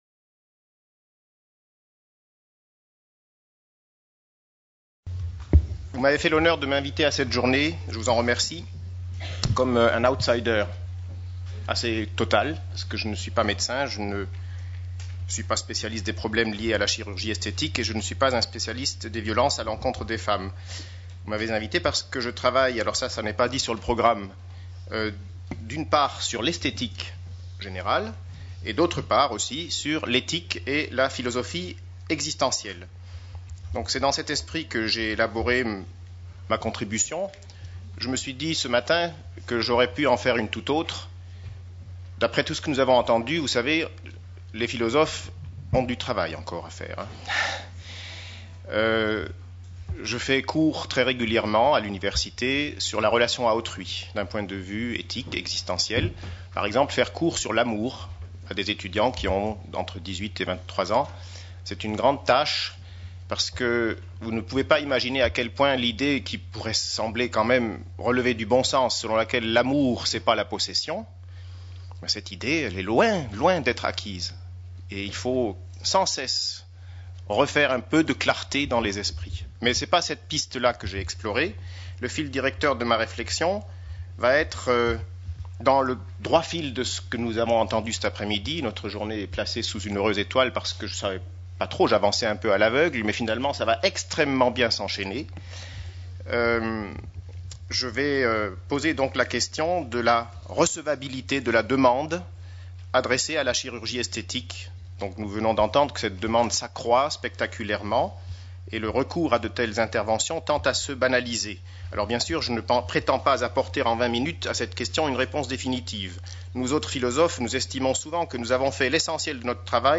4ème Journée Humanitaire sur la Santé des Femmes, organisée par Gynécologie Sans Frontières, le 29 novembre 2013, au Palais du Luxembourg (Paris). De la culture traditionnelle à la mode, quand la chirurgie devient une autre forme de violence faite aux femmes.